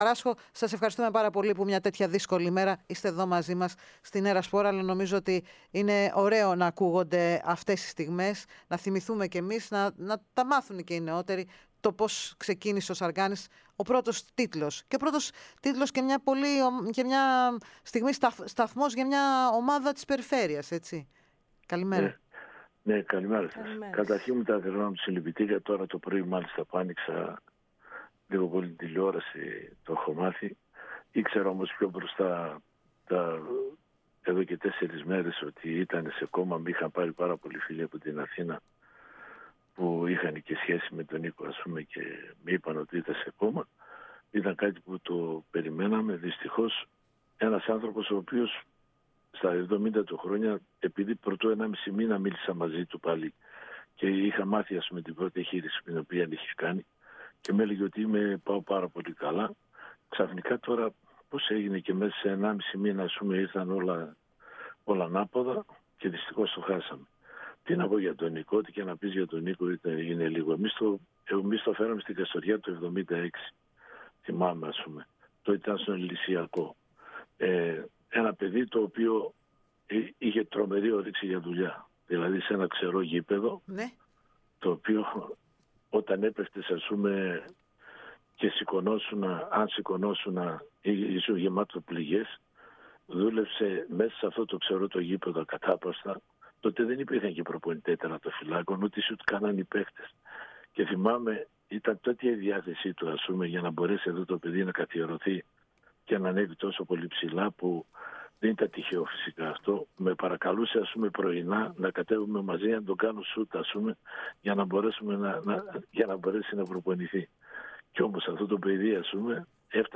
O γνωστός προπονητής βγήκε στον "αέρα" της ΕΡΑ ΣΠΟΡ και την εκπομπή "Οι Τρισδιάστατοι", προκειμένου να μιλήσει για την απώλεια του σπουδαίου τερματοφύλακα, με τον οποίο υπήρξε συμπαίκτης στην Καστοριά και στην Εθνική ομάδα.